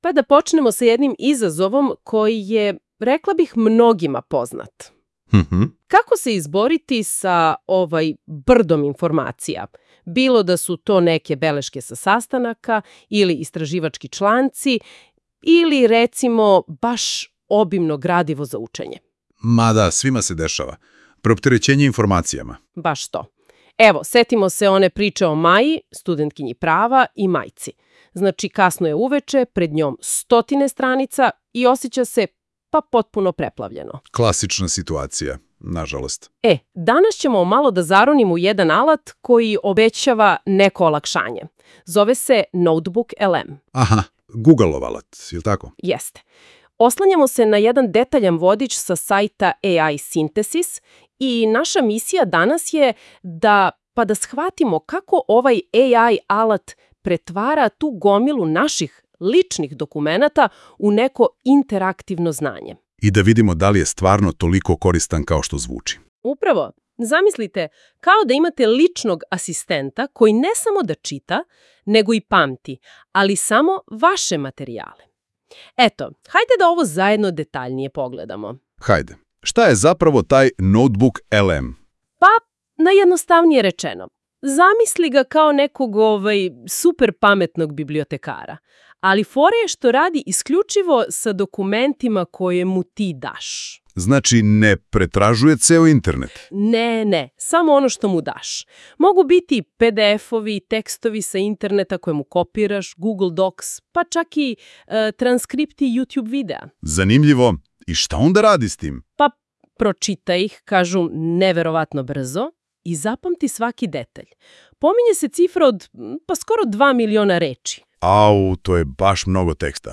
Poslušajte gore kako zvuči NotebookLM podcast – generisan je upravo iz ovog članka koji čitate!